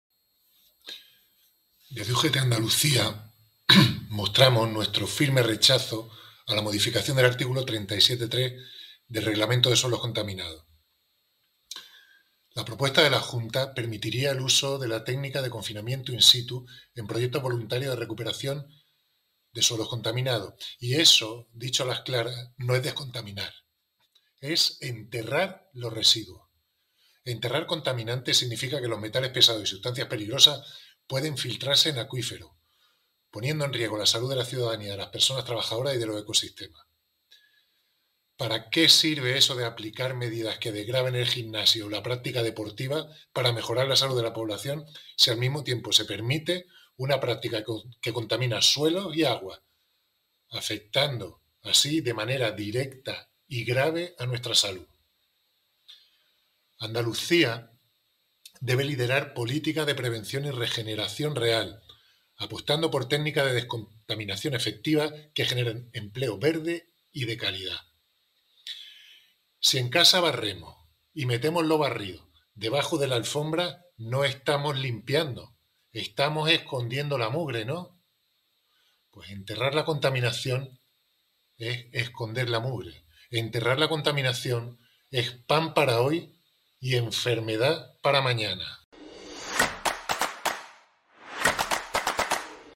Audio de valoración